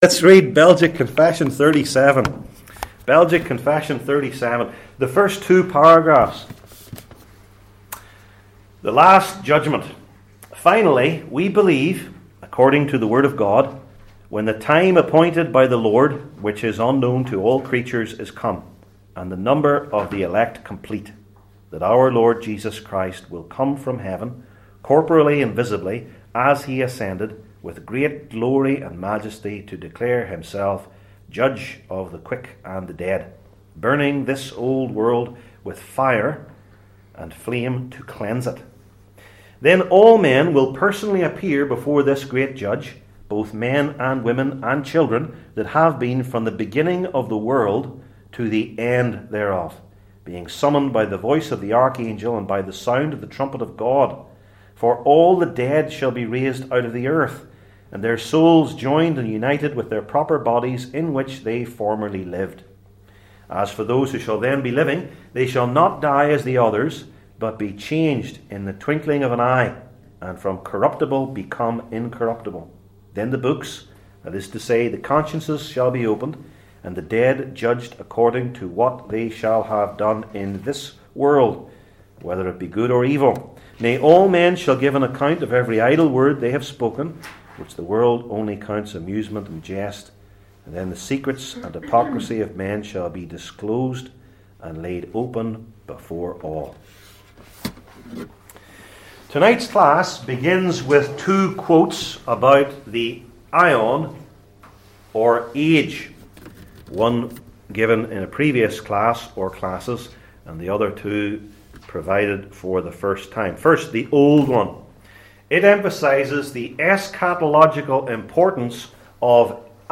Service Type: Belgic Confession Classes